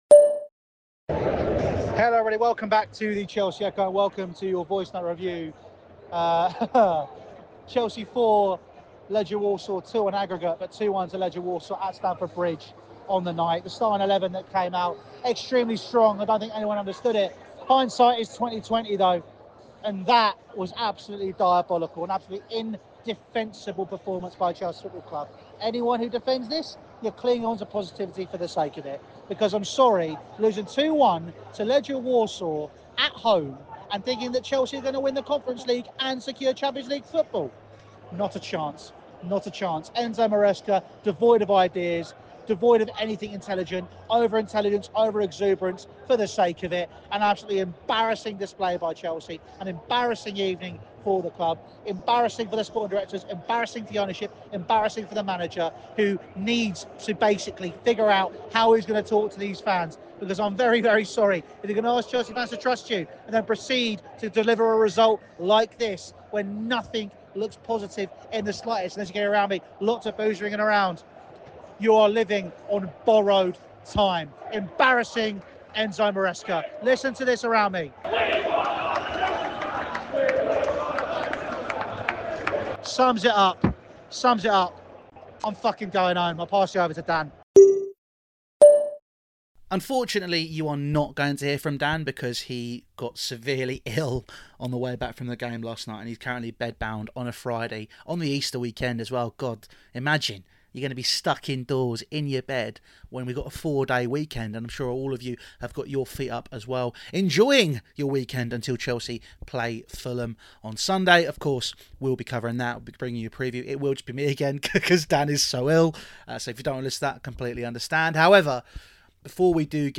Small-time mentality | Chelsea 1-2 Legia Warsaw | Voicenote Review